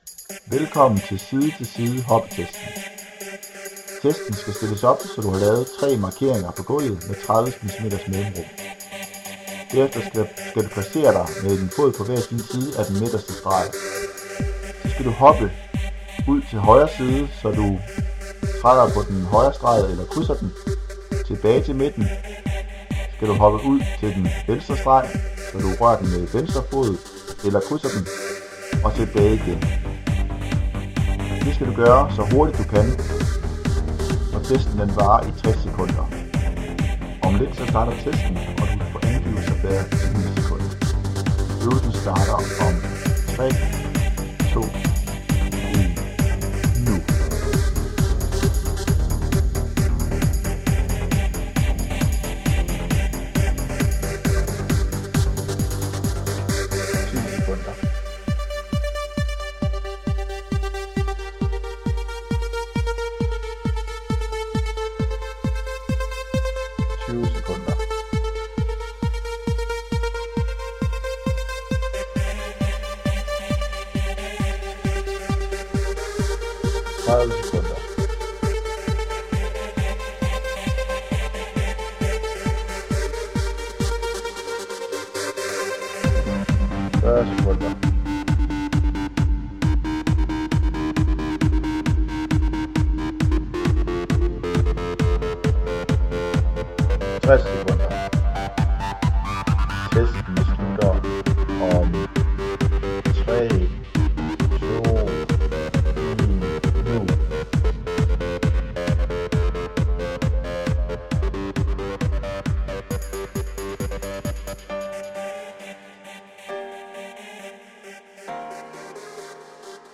Speak og musik til side til side hoppetest.